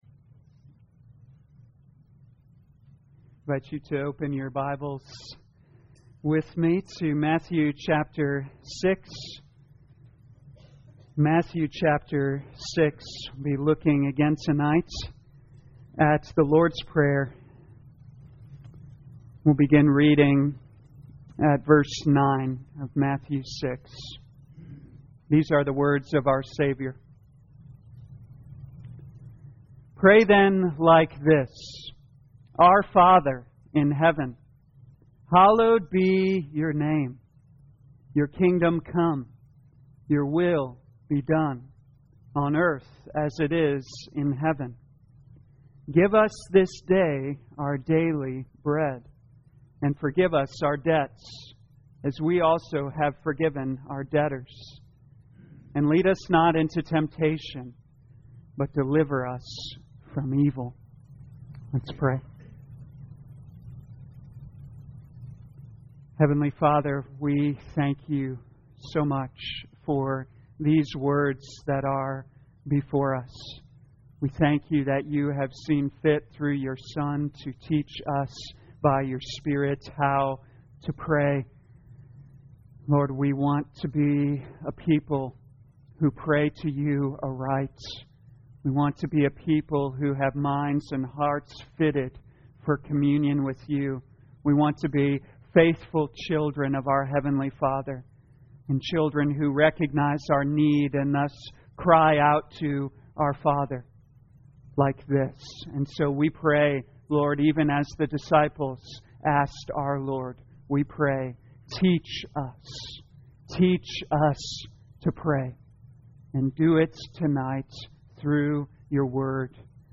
2021 Matthew Prayer Evening Service Download